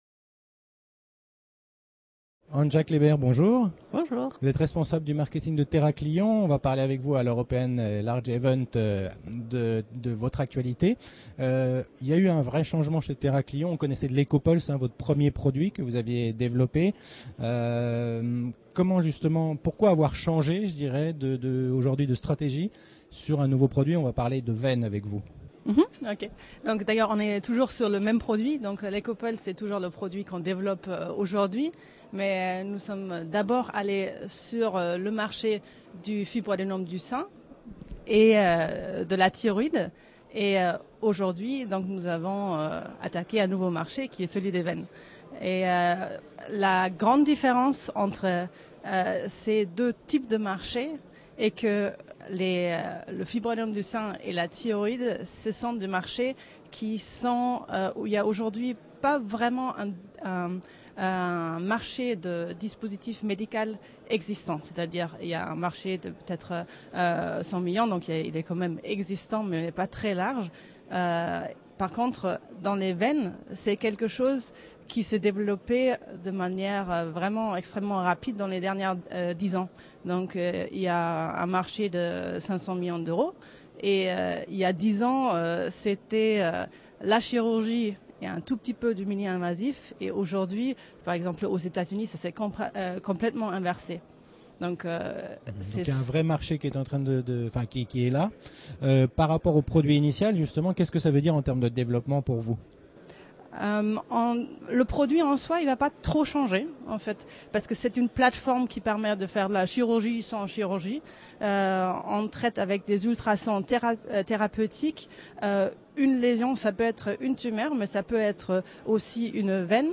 La Web Tv rencontre les dirigeants au Paris - European Large et Midcap Event